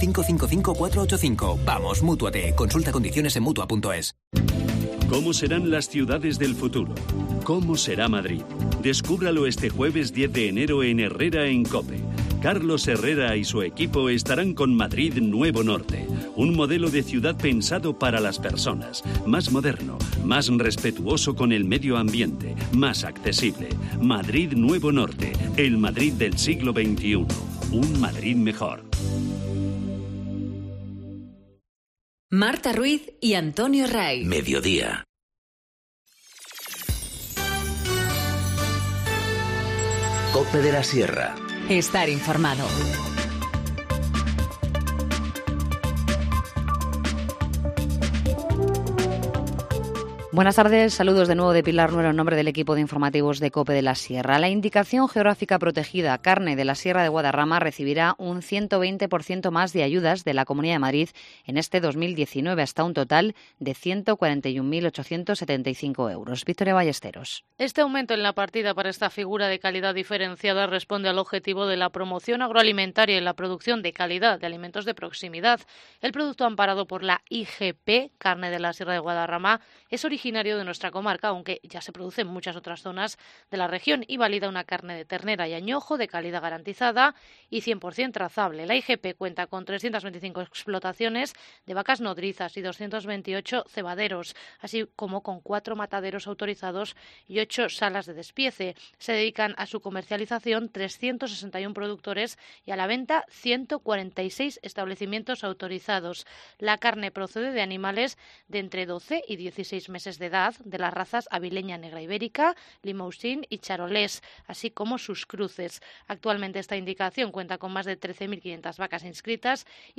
Informativo Mediodía 8 enero- 14:50h